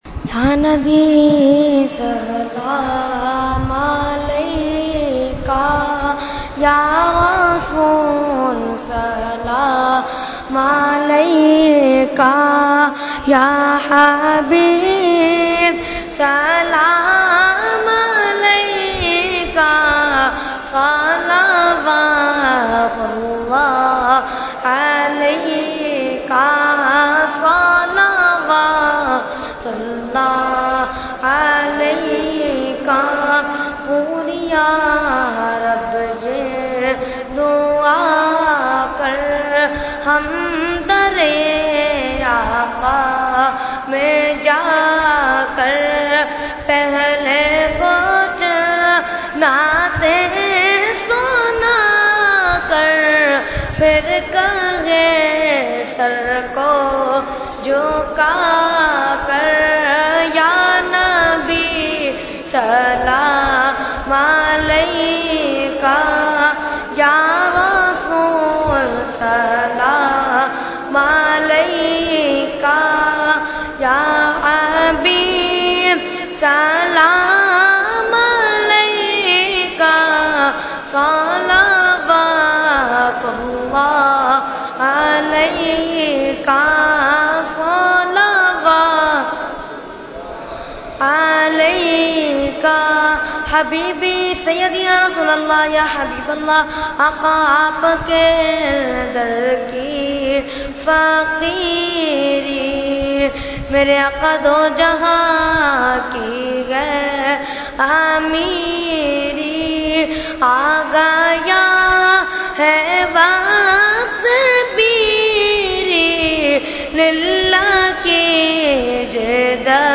Category : Salam | Language : UrduEvent : Dars Quran Farooqi Masjid 8 June 2012